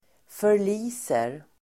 Uttal: [för_l'i:ser]